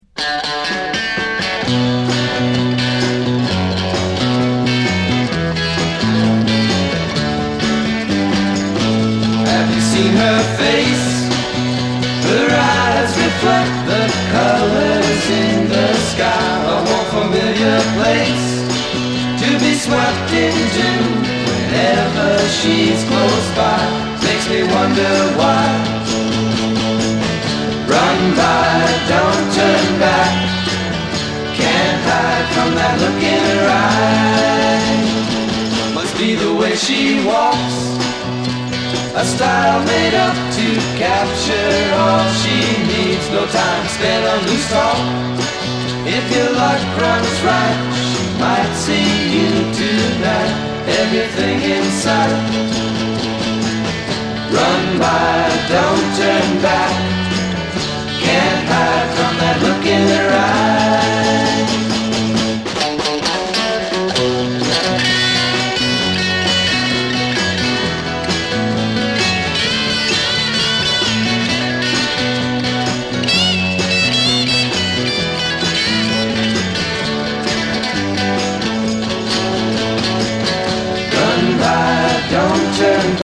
西海岸フォークロックバンド